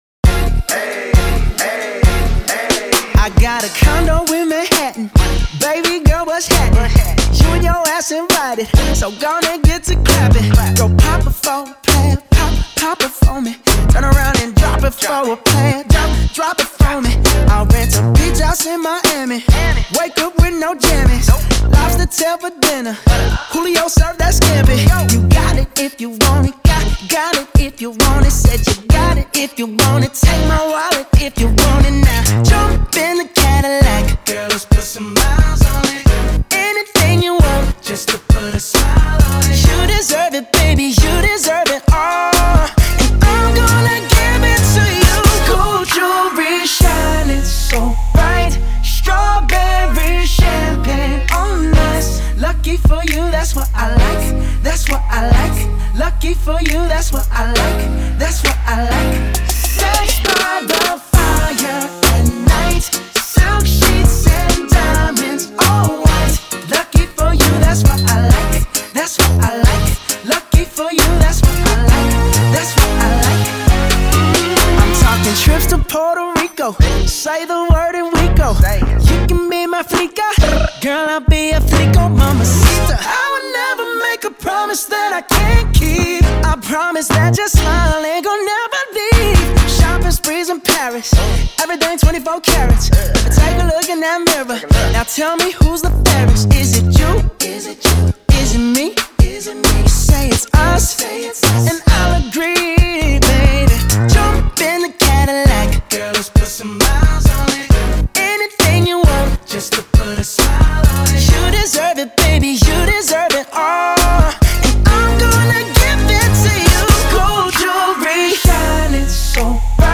Pop/R&B